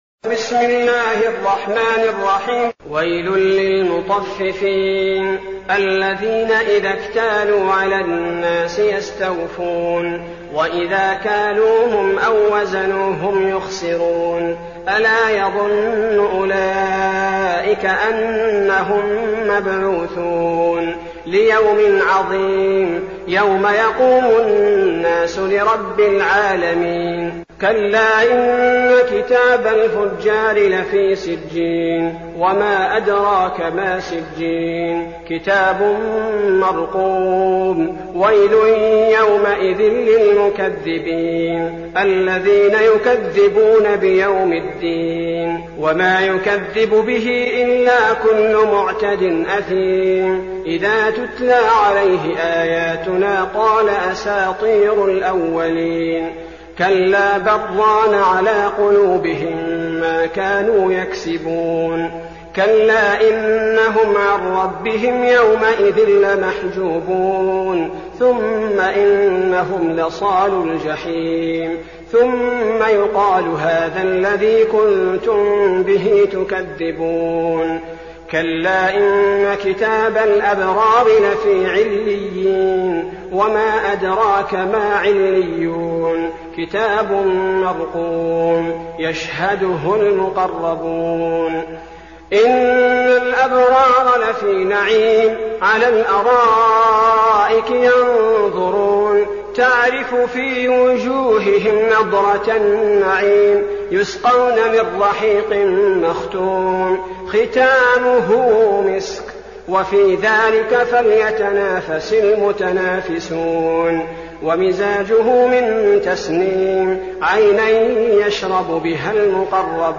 المكان: المسجد النبوي الشيخ: فضيلة الشيخ عبدالباري الثبيتي فضيلة الشيخ عبدالباري الثبيتي المطففين